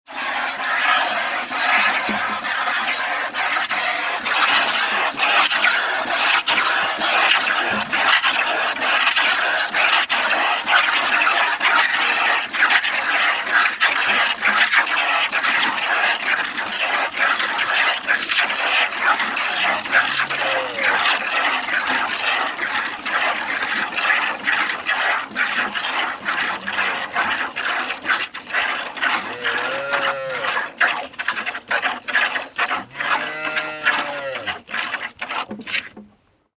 Farming tasks
Hand milking into a bucket
hand_milking_into_a_bucket.mp3